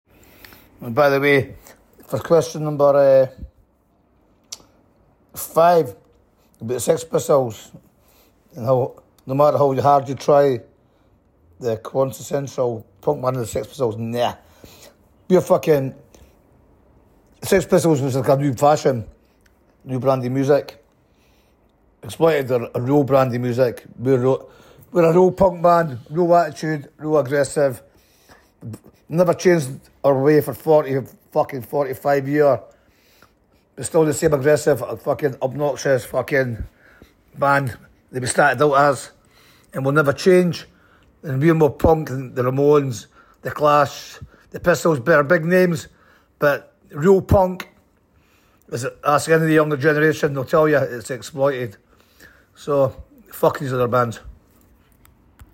Entrevista a Wattie Buchan de The Exploited, antes de su concierto en Córdoba